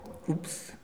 ajout des sons enregistrés à l'afk ... Les sons ont été découpés en morceaux exploitables. 2017-04-10 17:58:57 +02:00 146 KiB Raw Permalink History
oups_02.wav